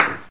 taiko-normal-hitclap.ogg